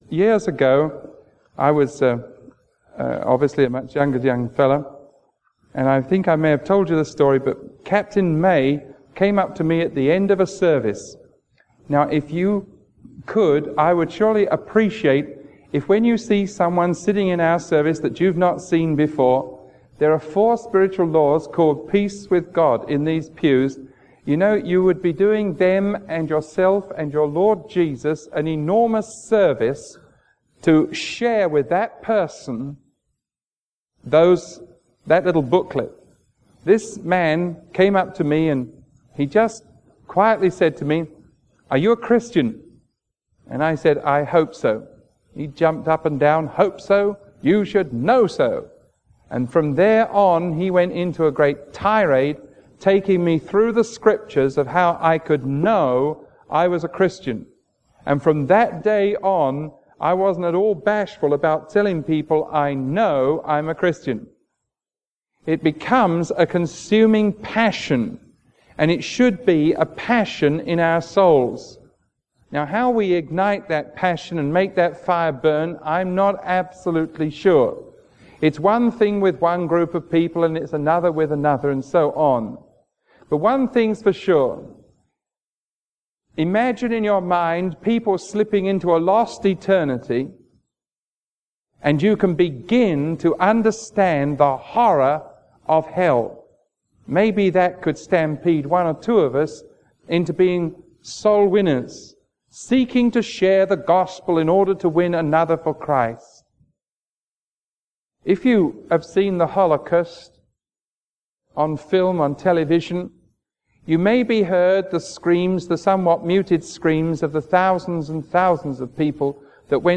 Sermon 0357AB recorded on May 27